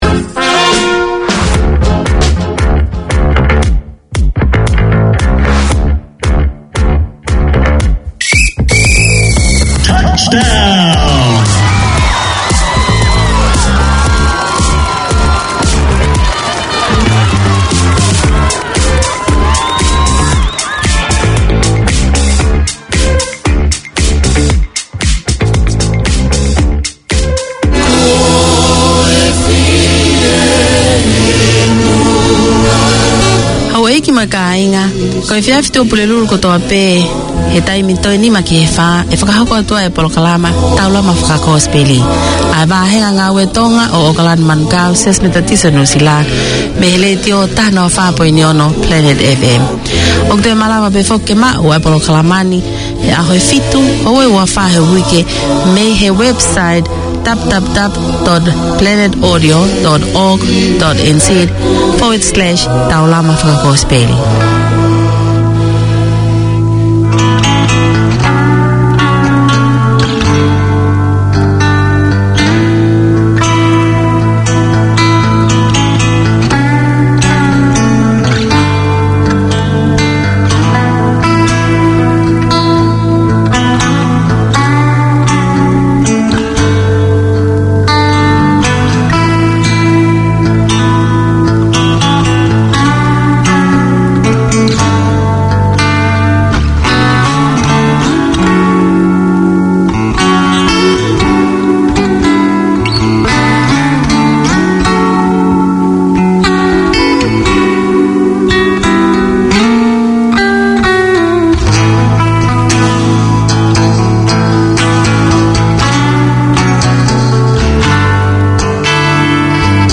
Presented by an experienced English teacher who understands the needs of his fellow migrants, these 30 minute lessons cover all aspects of English including Pronunciation, Speaking, Listening, Reading and Writing. Hear how to improve English for general and workplace communication, social interactions, job interviews, IELTS and academic writing, along with interviews with migrants and English experts.